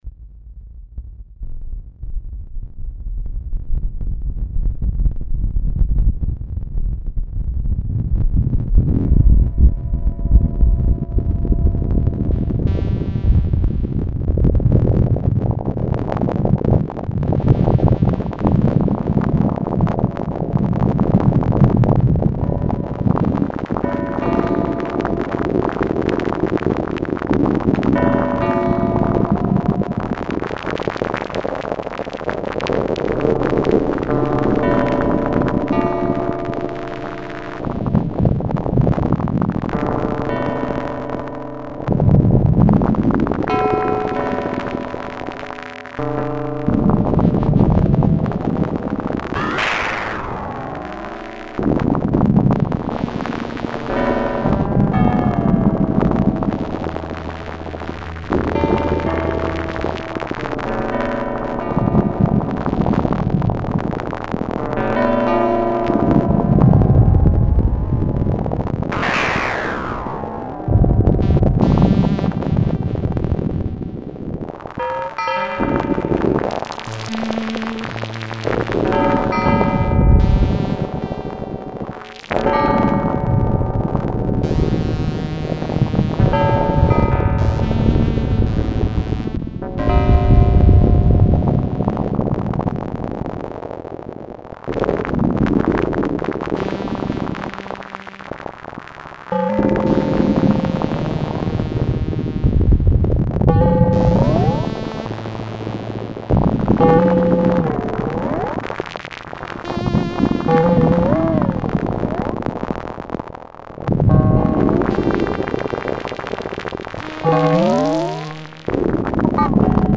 Oomingmak turns your guitar into an analogue monosynth.
The sounds below are examples of what Oomingmak can do.
Uses only dry guitar and Oomingmak.